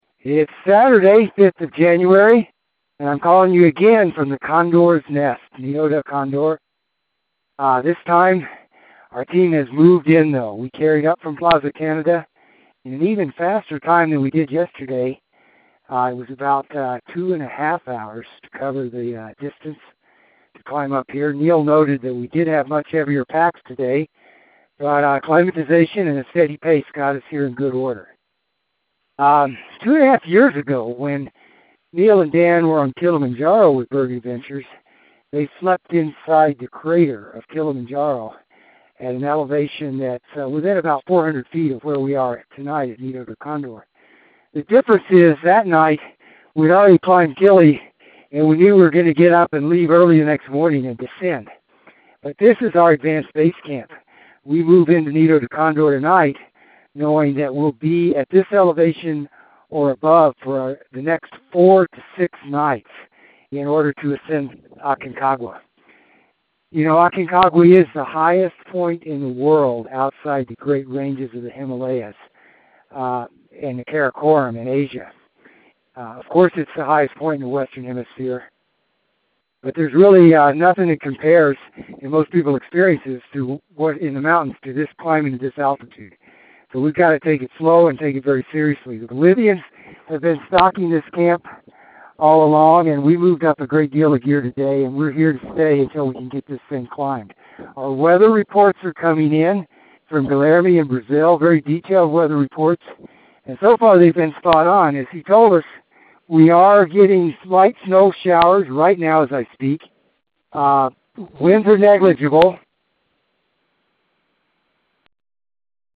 January 5, 2013 – Nido de Condor Camp – the Waiting Game Starts